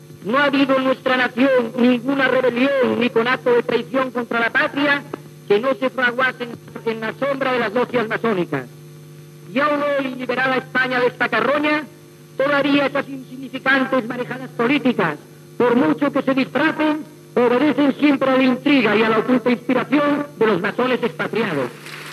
Discurs del "generalísimo" Franco davant del Consejo Nacional on parla sobre la maçoneria.
Informatiu